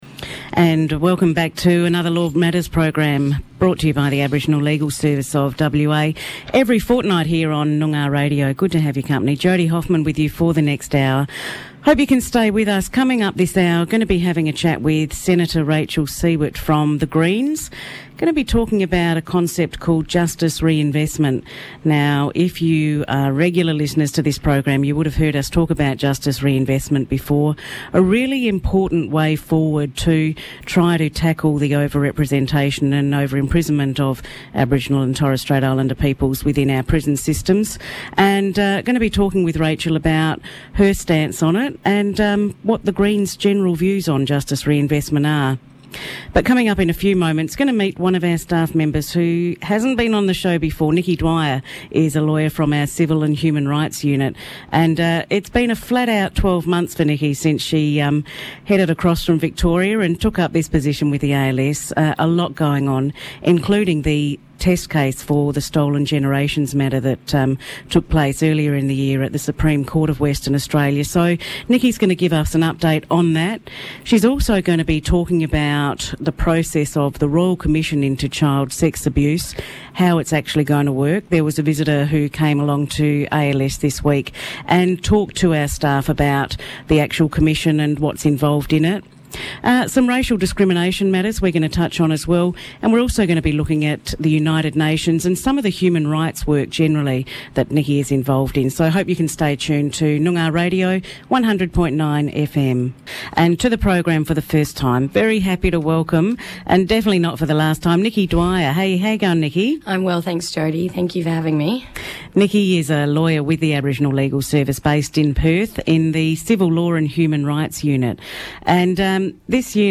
Guests: Senator Rachel Siewert, Green State Senator; Rachel discusses the importance of Justice Reinvestment